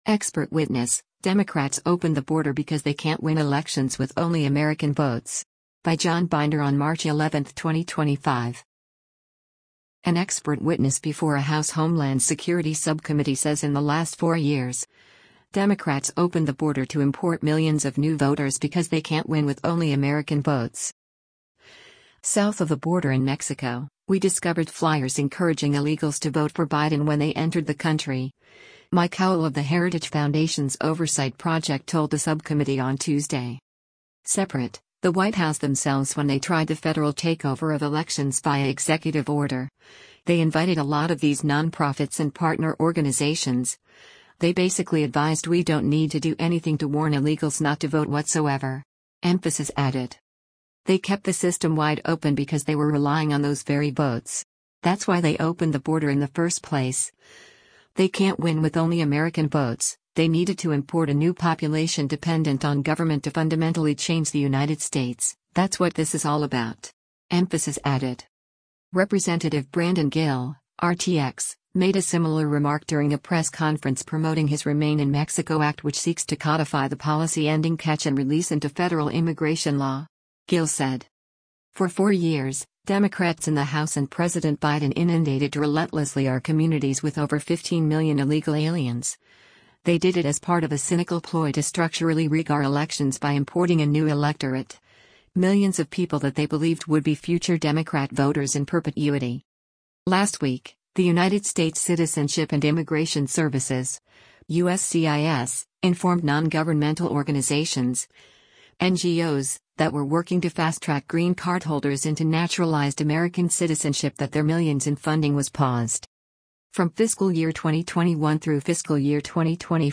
An expert witness before a House Homeland Security Subcommittee says in the last four years, Democrats “opened the border” to import millions of new voters because “they can’t win with only American votes.”